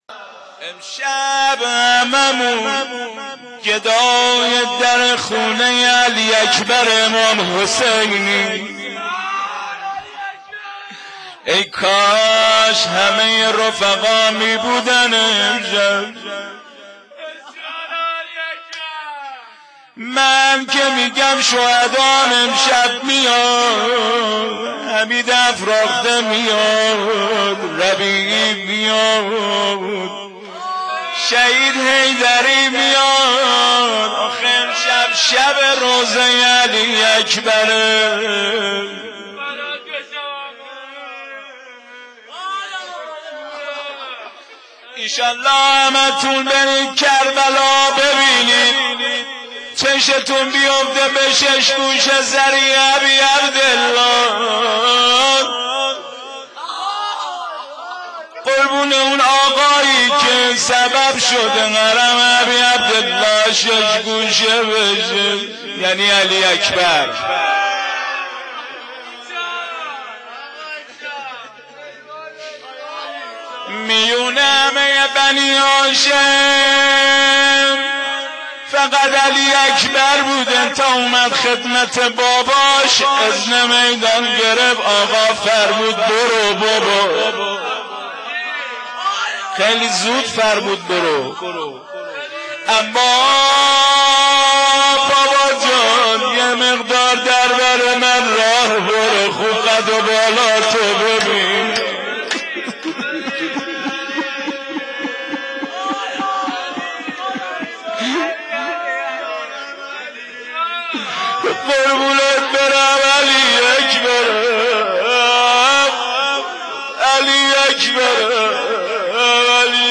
روضه حضرت علی اکبر 1384
roze-ali-akbar-1-1384.wma